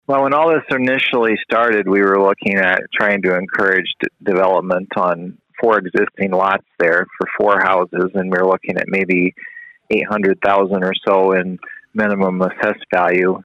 City Manager Sam Kooiker tells us about the original plan for a housing development on the site.